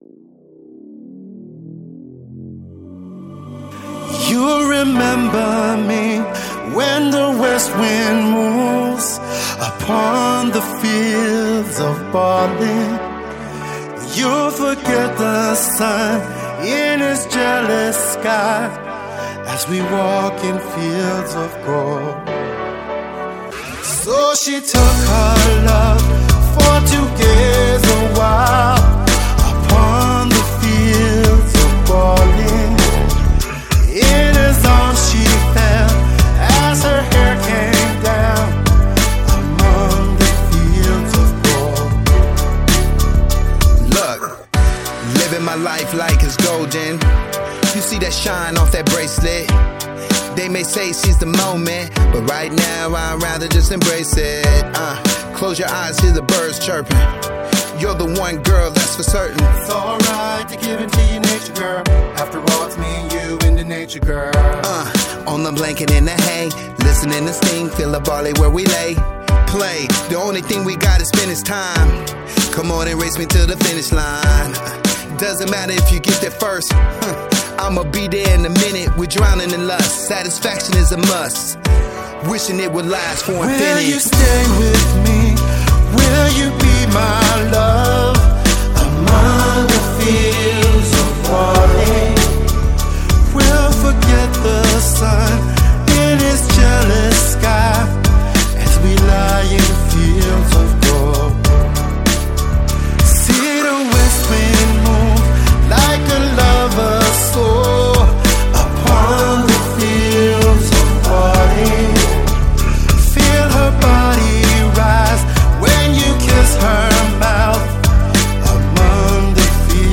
Жанр: rnb